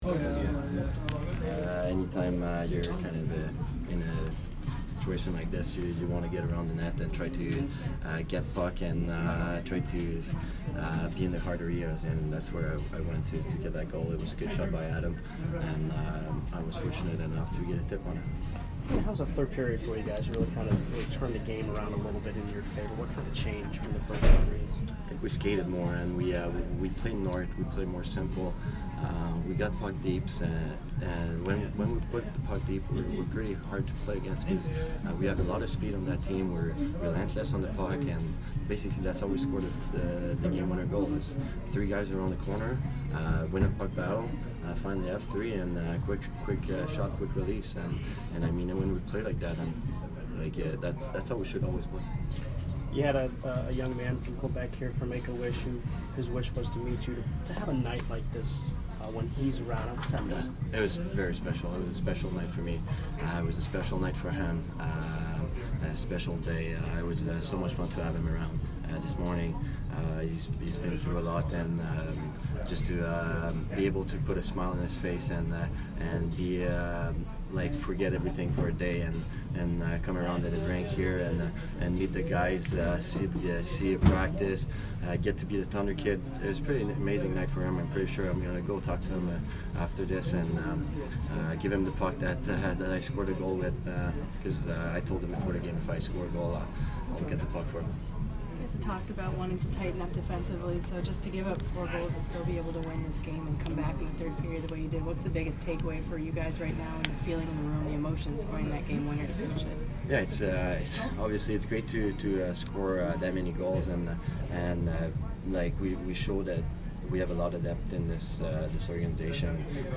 Yanni Gourde post-game 12/29